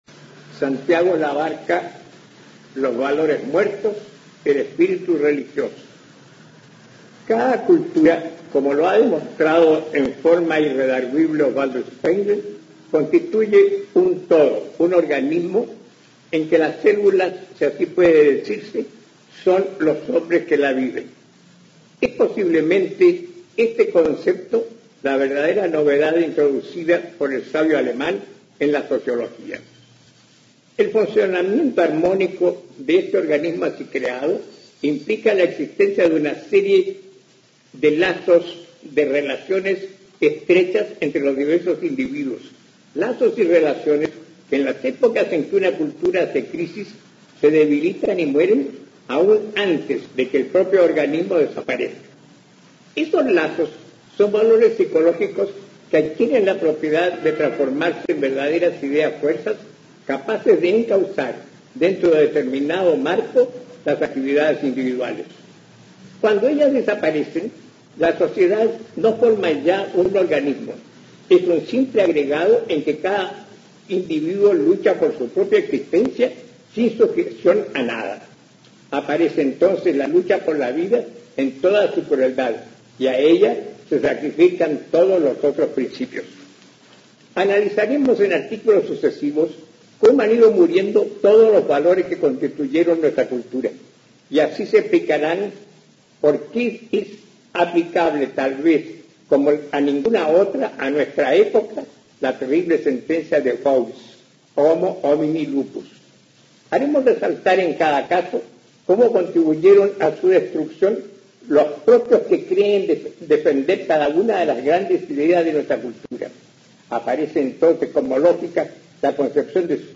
Aquí se puede escuchar al intelectual chileno Santiago Labarca leyendo su artículo Los valores muertos. El espíritu religioso, aparecido en el diario La Nación el año 1924, y en el que se refiere a la intolerancia tanto religiosa como laica.